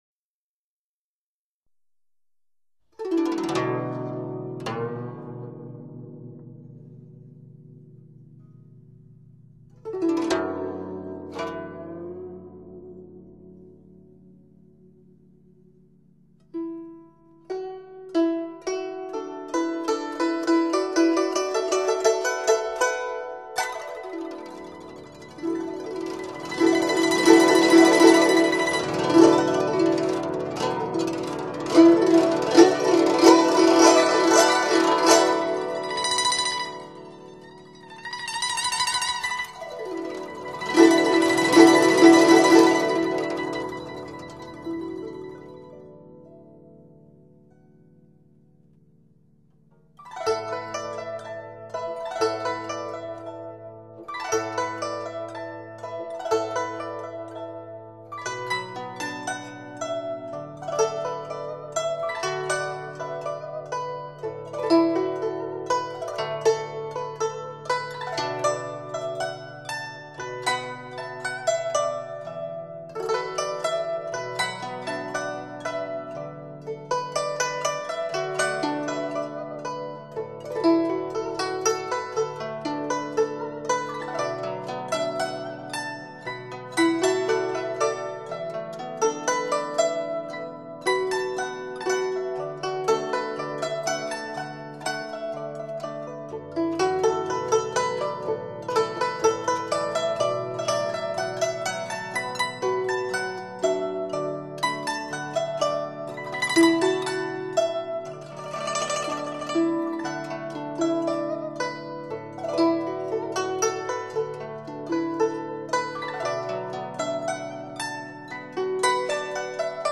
古筝